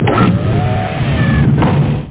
Amiga 8-bit Sampled Voice
hatch.mp3